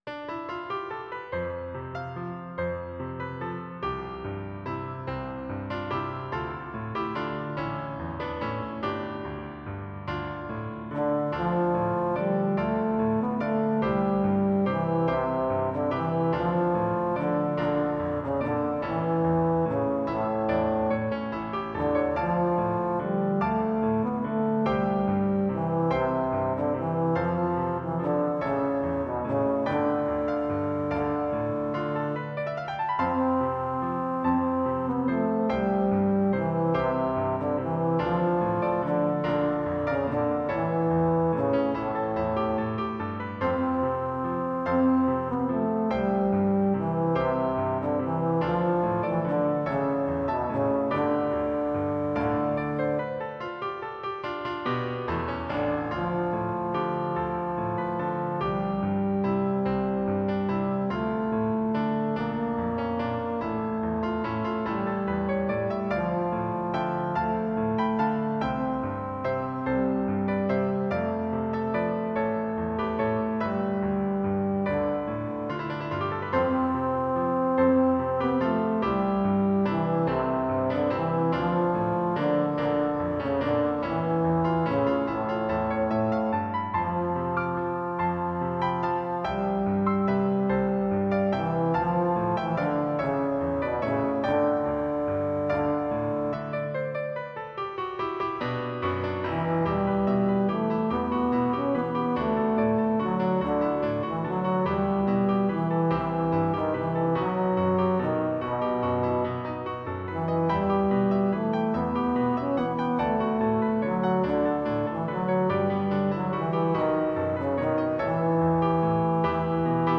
These are for low brass solo with piano accompaniment.
for trombone and piano
Trombone and piano
The recordings use a simulated trombone.